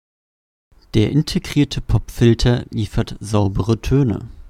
Hier ist eine Aufnahme mit Unterschreitung der 15cm.
Generell sollte der empfohlene Sitzabstand von mindestens 15 cm eingehalten werden, da sonst kleine Schmatzer und Übersteuerungen selbst mit der besten Technik kaum zu vermeiden sind.